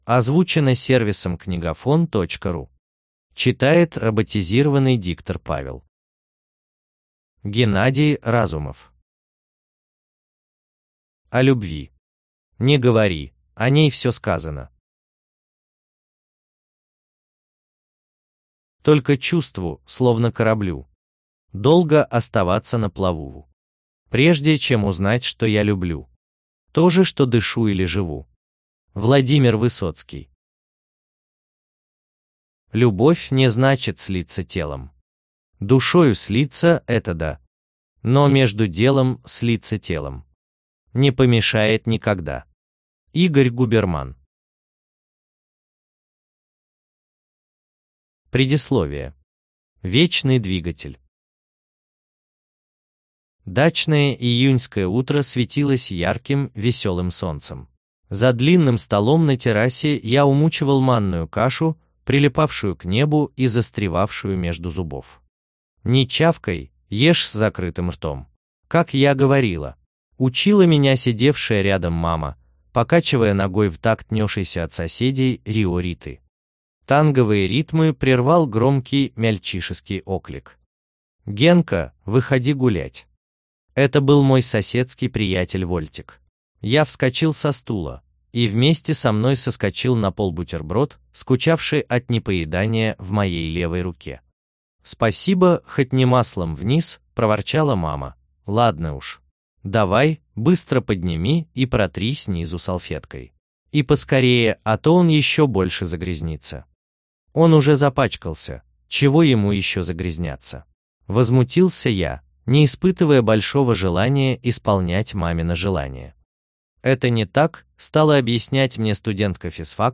Аудиокнига О любви | Библиотека аудиокниг